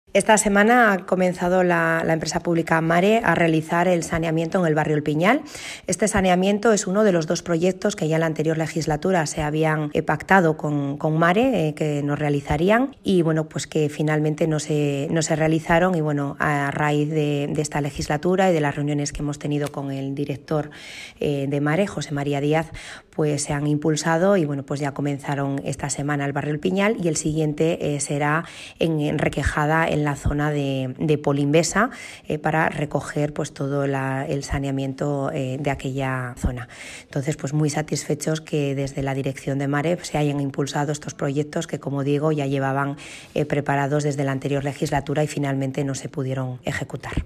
Audio de Rosa Díaz Fernández
Alcaldesa-sobre-proyectos-de-saneamiento-en-POLANCO.mp3